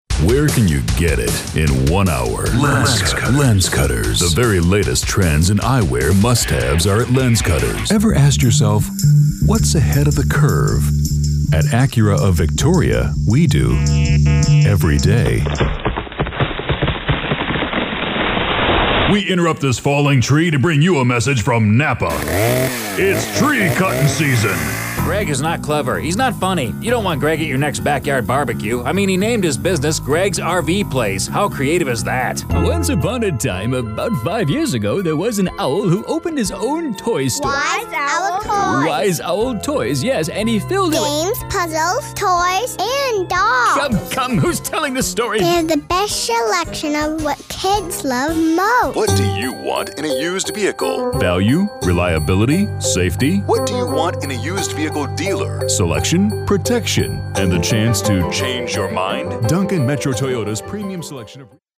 123AudioAds Voiceover Artists
English - Male